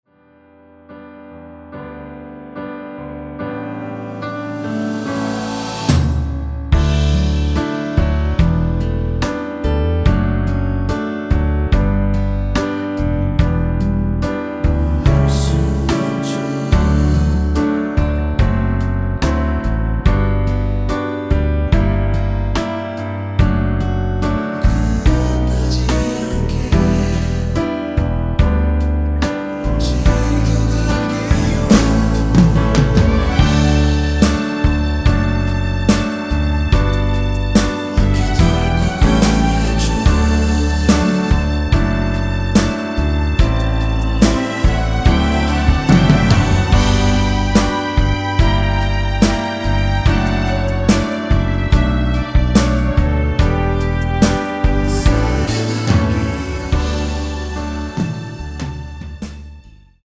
원키에서(-1)내린 코러스 포함된 MR입니다.(미리듣기 확인)
앞부분30초, 뒷부분30초씩 편집해서 올려 드리고 있습니다.
중간에 음이 끈어지고 다시 나오는 이유는